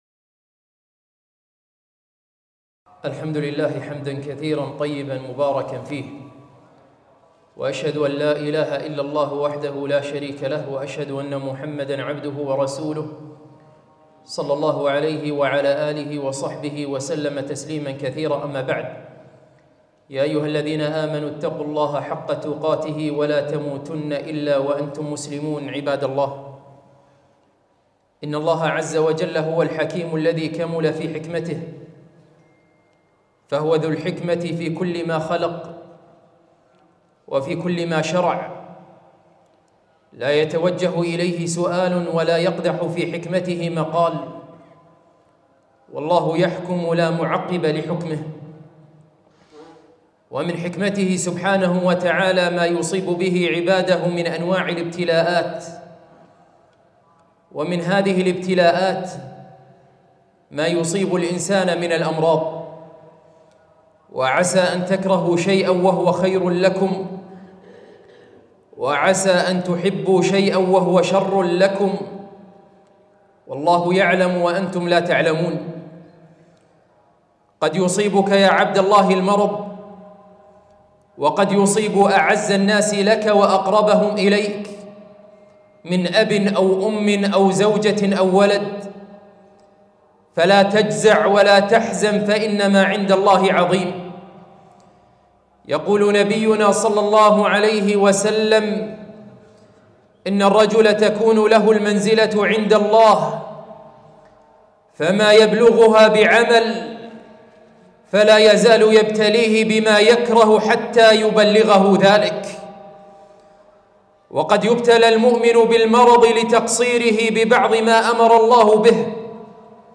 خطبة - رسالة إلى مريض